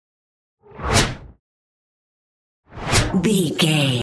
Whoosh fast x2
Sound Effects
Fast
futuristic
intense
whoosh